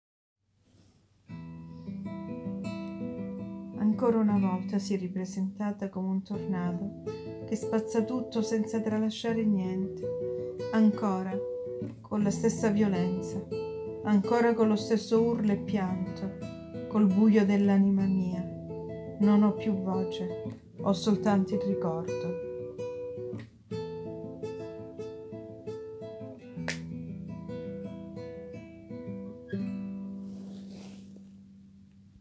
read it for me on a beautiful guitar sound:
Also I can add that it has a soft sound, even if here it has a suffered interpretation.